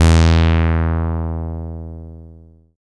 78.03 BASS.wav